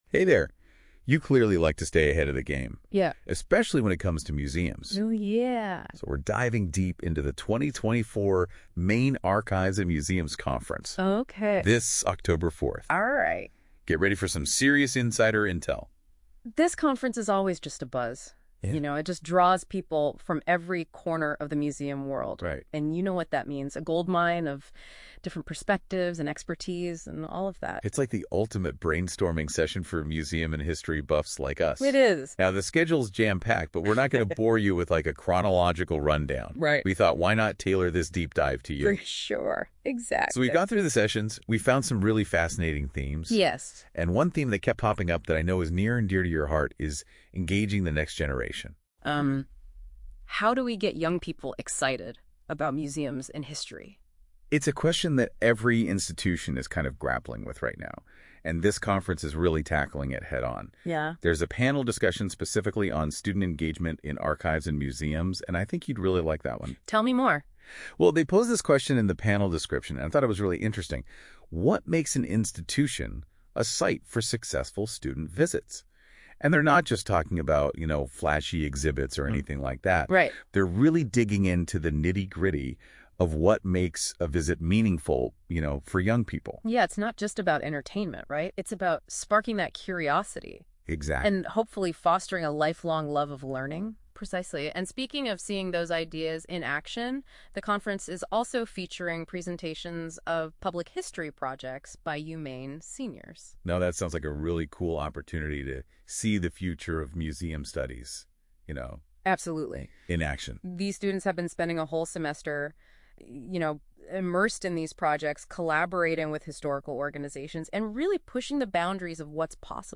Summaries NotebookLM podcast generated from MAM schedule